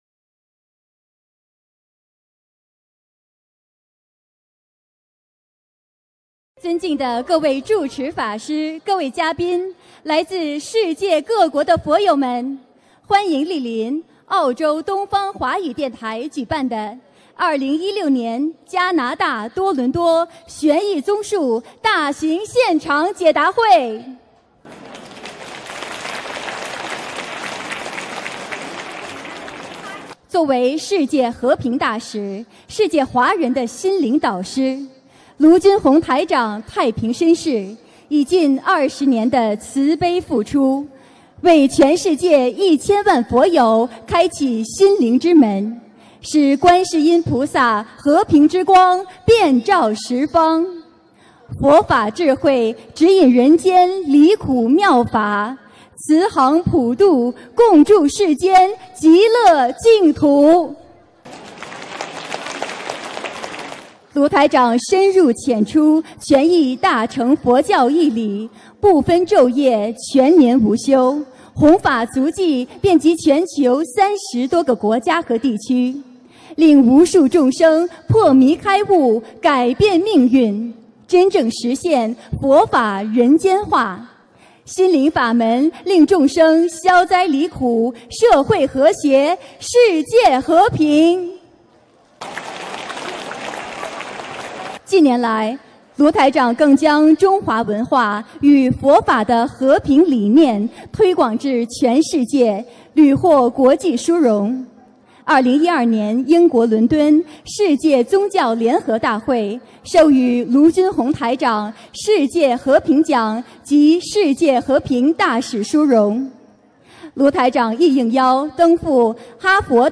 2016年9月5日加拿大多伦多解答会开示（视音文图） - 2016年 - 心如菩提 - Powered by Discuz!